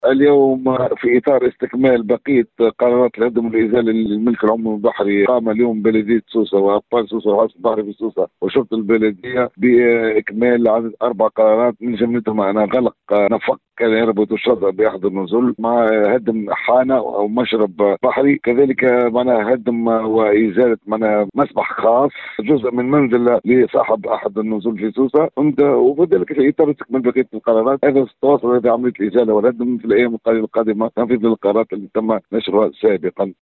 تصريح ل”ام اف ام ” اليوم